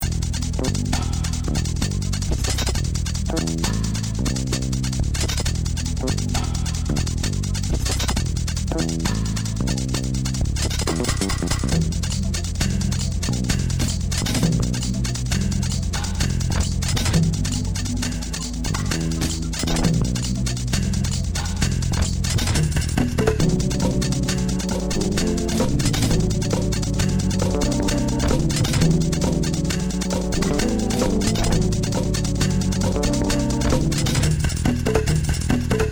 Old stuff from my early breakbeat years.
Vieux morceaux de mes débuts en breakbeat.